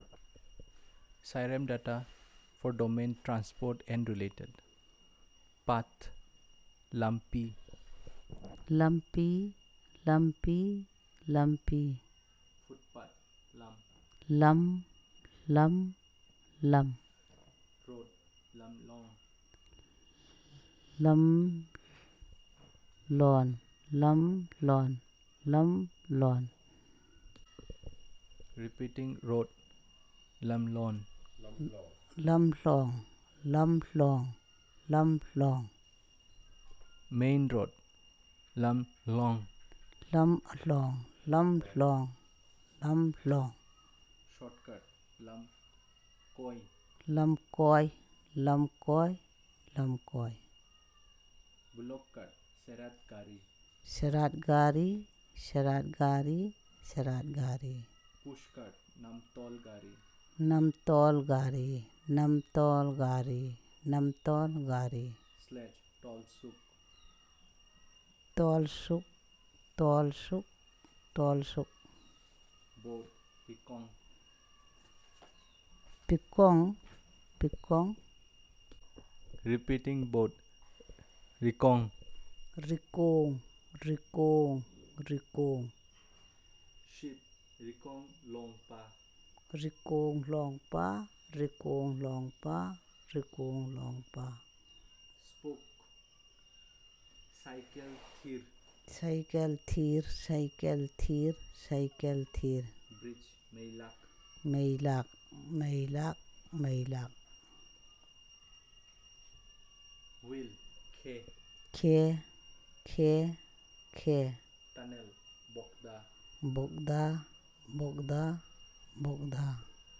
Elicitation of words about transport and related
NotesThis is an elicitation of words about transport and related using the SPPEL Language Documentation Handbook.